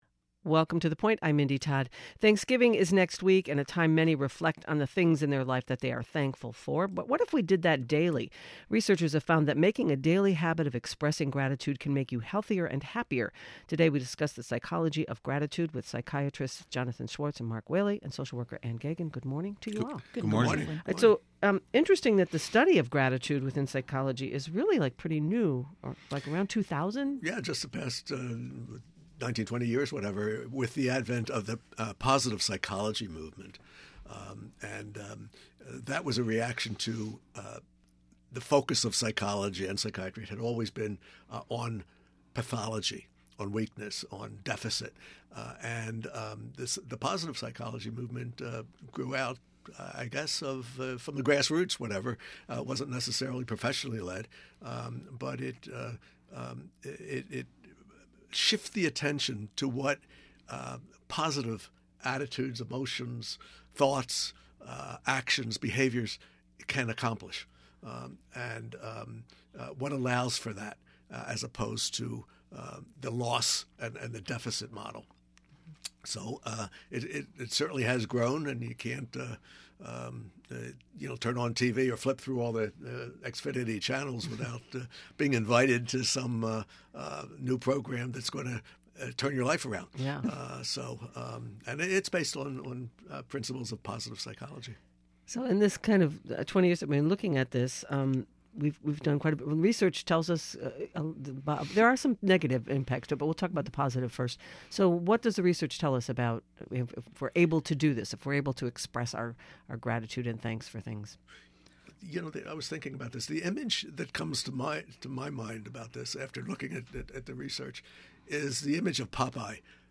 WCAI's award-winning public affairs program.